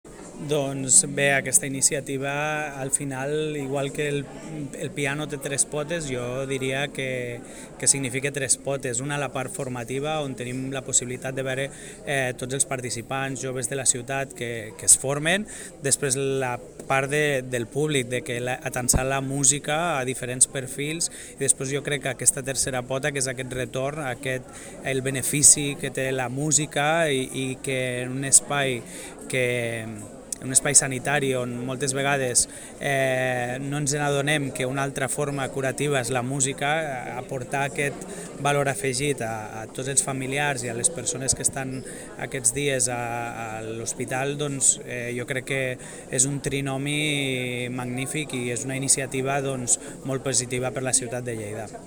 Fitxers relacionats El regidor de Joventut, Educació i Ocupació, Xavier Blanco, ha destacat que l'activitat apropa la música a tothom i, a més, ofereix un espai a joves intèrprets on poder tocar (810.6 KB)